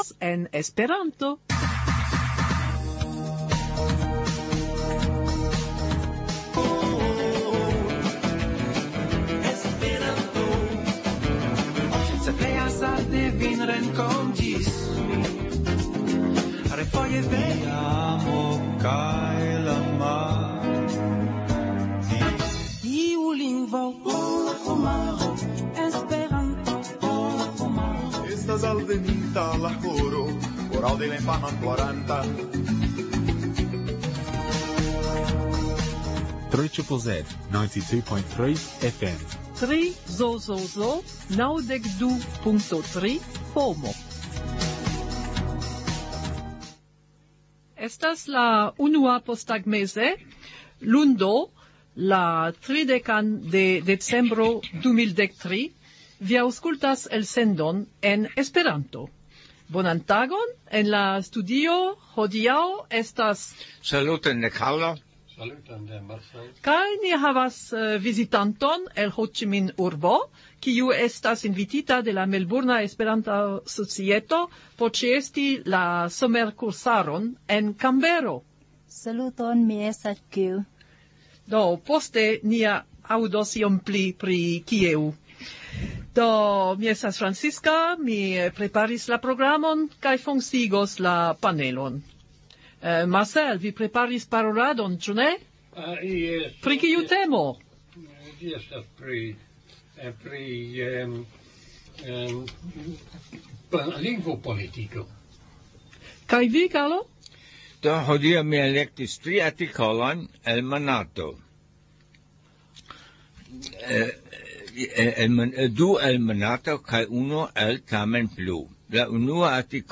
Intervjuo.